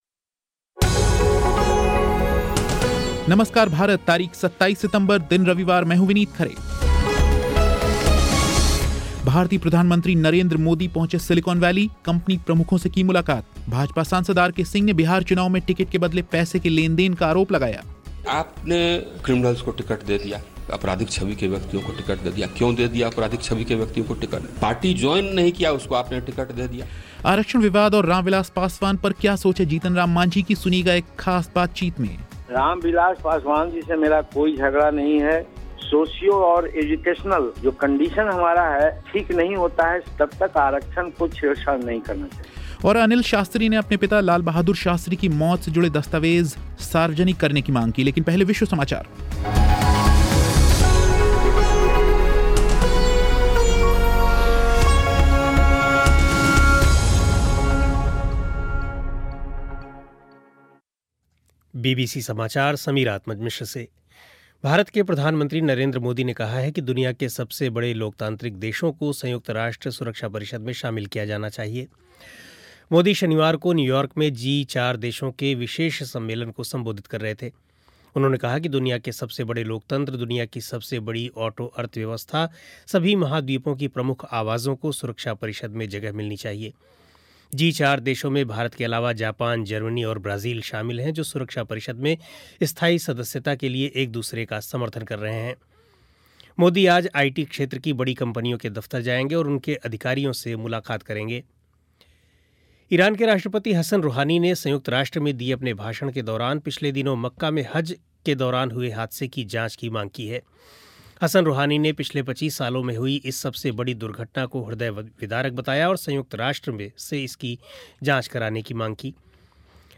नरेंद्र मोदी पहुंचे सिलिकॉन वैली, कंपनी प्रमुखों से की मुलाकात. भाजपा सांसद आरके सिंह ने बिहार चुनाव में टिकट के बदले पैसे के लेन-देन का आरोप लगाया. आरक्षण विवाद औऱ राम विलास पासवान पर क्या सोच है जीतनराम मांझी की, सुनिएगा एक खास बातचीत. अनिल शास्त्री ने अपने पिता लाल बहादुर शास्त्री की मौत से जुड़े दस्तावेज सार्वजनिक करने की मांग की.